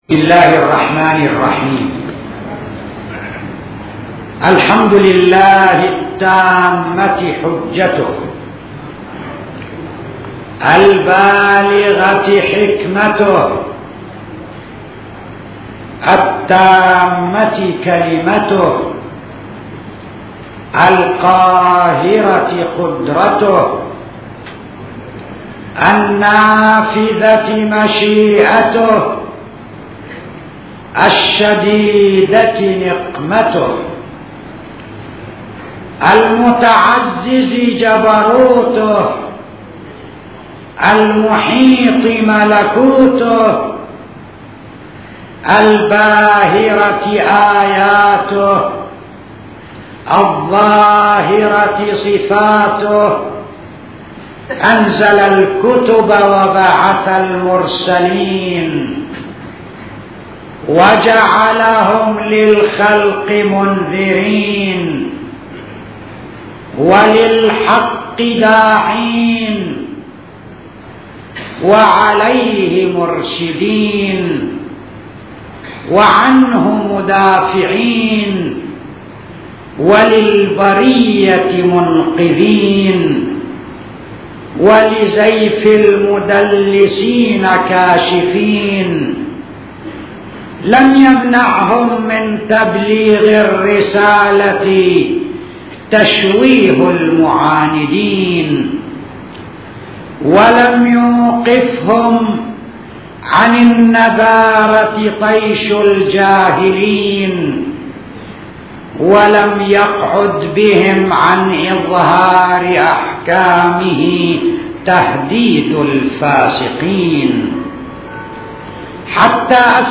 صوتيات | خطب